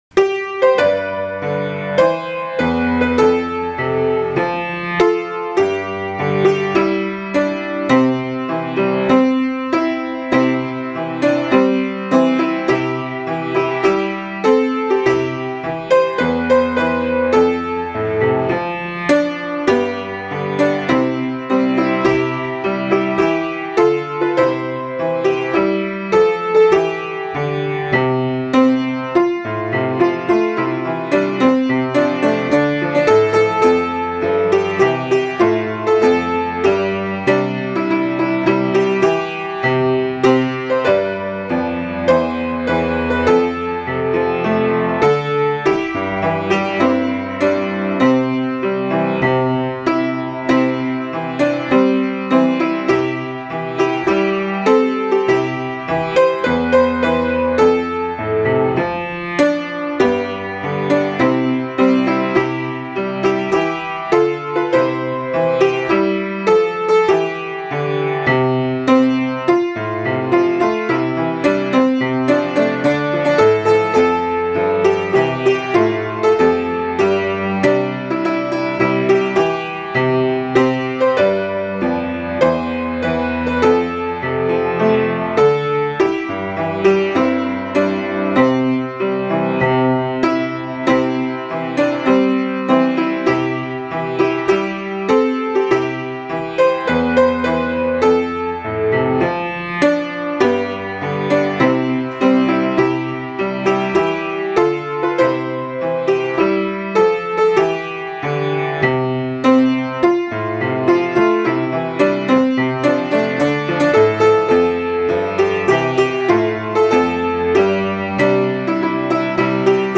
（♪ここをクリックすると伴奏が聞けます♪）